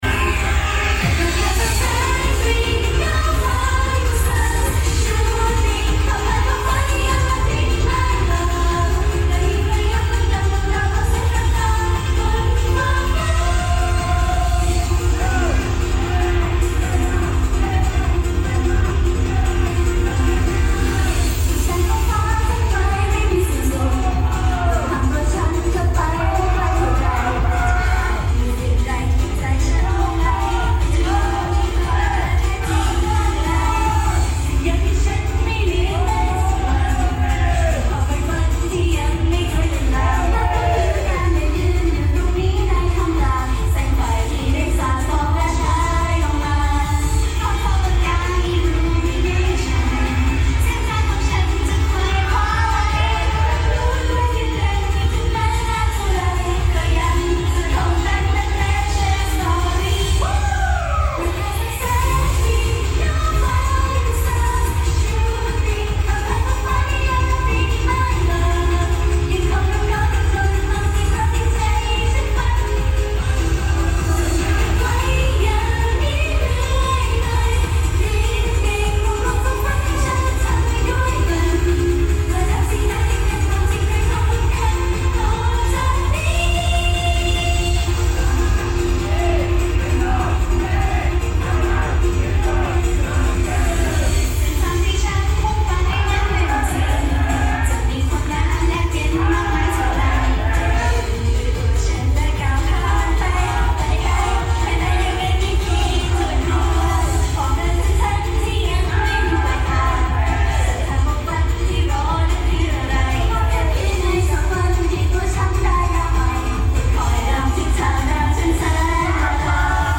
Siamdol Festival
DONKI Mall Thonglor, 4th Floor